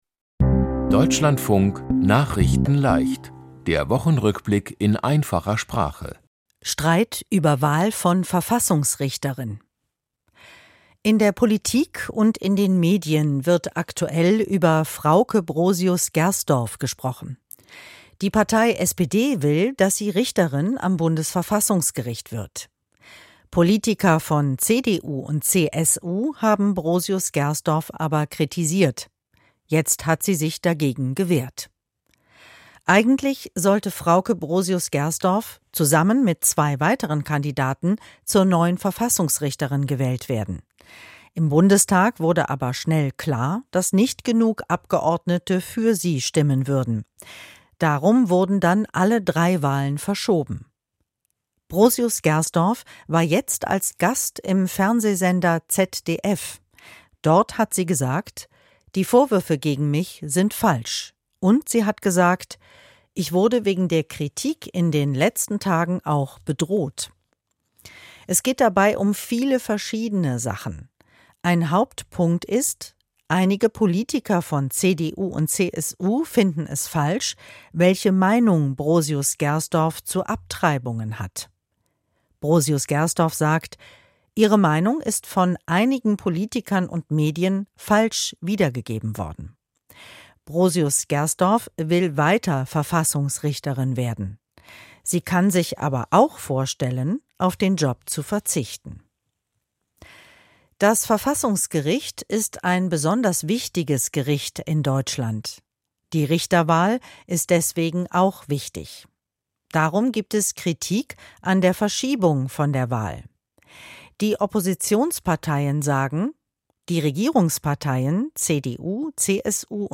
Die Themen diese Woche: Streit über Wahl von Verfassungs-Richterin, Neue Kämpfe in dem Land Syrien, Mehr Babys auf der ganzen Welt geimpft, Wenig Menschen mit Behinderung in Führung von Wohlfahrts-Verbänden, Schloss Neuschwanstein ist jetzt Welt-Kultur-Erbe und Schwimmer Florian Wellbrock ist Welt-Meister. nachrichtenleicht - der Wochenrückblick in einfacher Sprache.